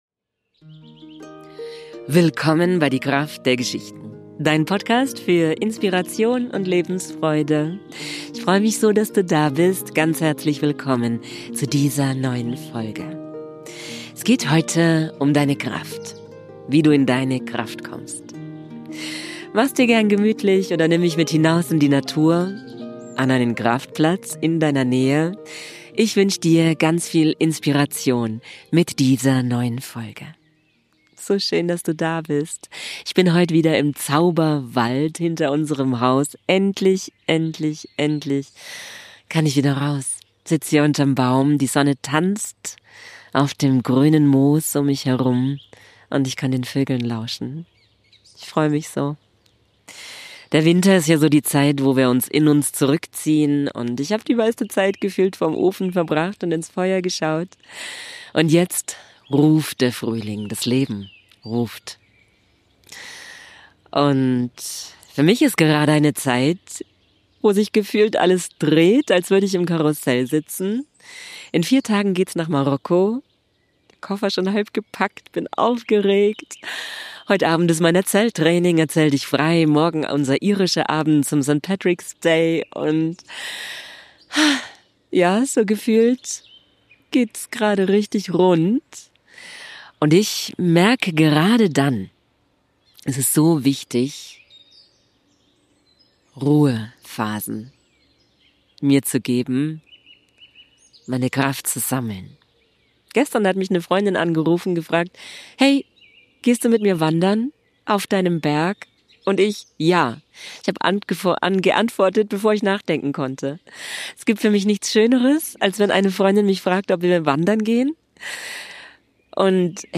Gerade wenn wir viel zu tun haben, sind Momente der Ruhe so kostbar. Heute habe ich eine kraftvolle Meditation für Dich.